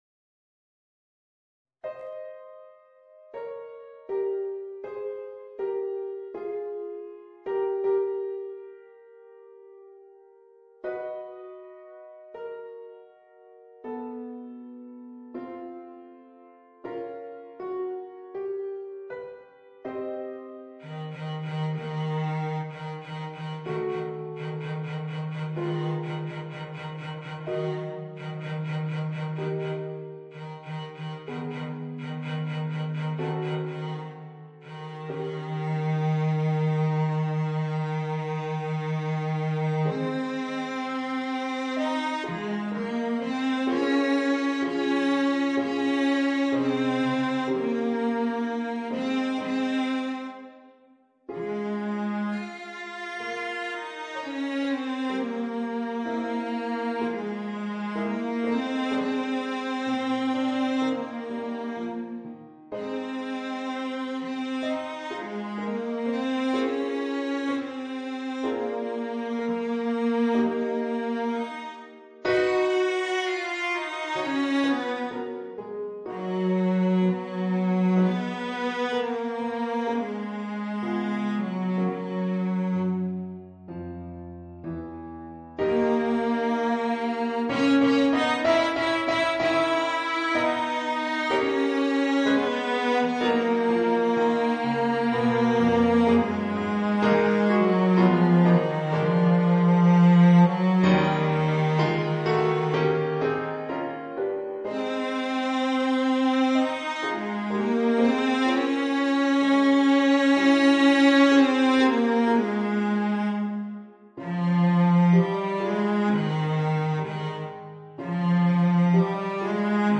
Voicing: Violoncello and Piano